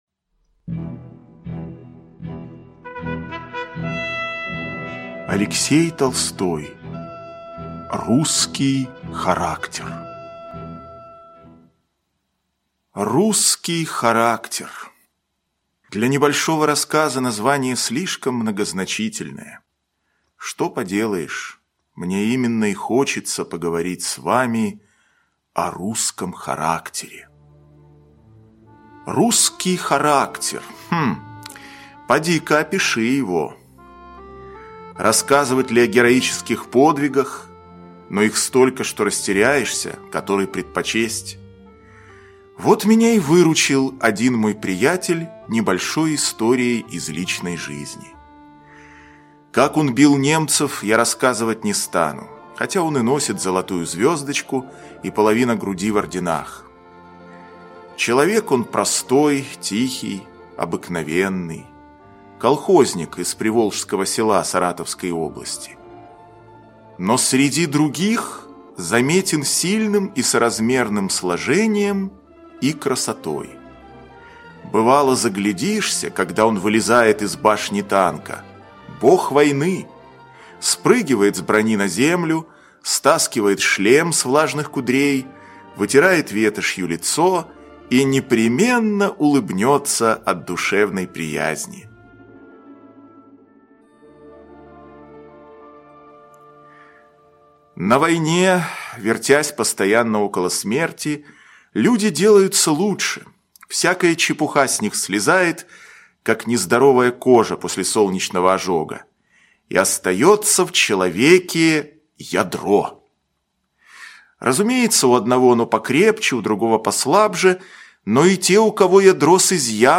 Русский характер - аудио рассказ Алексея Толстого - слушать онлайн